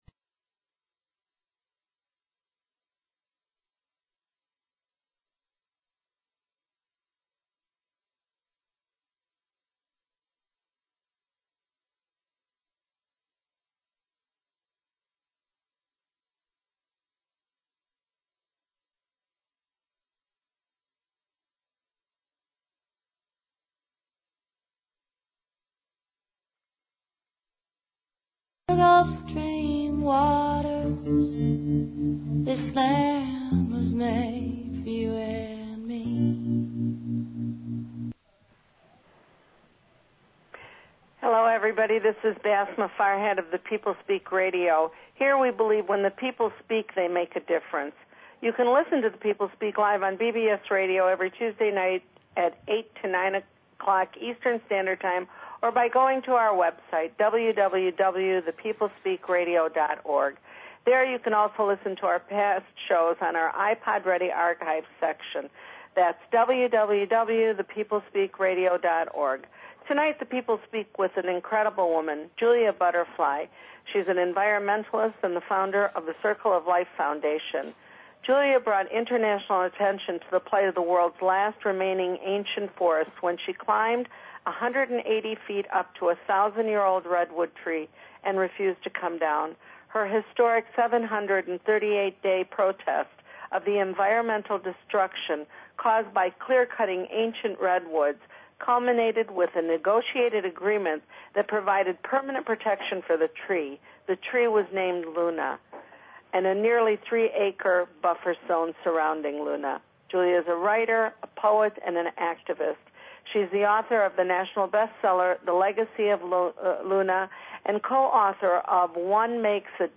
Talk Show Episode, Audio Podcast, The_People_Speak and Julia Butterfly on , show guests , about , categorized as Earth & Space,Education,History,Society and Culture,Spiritual
Guest, Julia Butterfly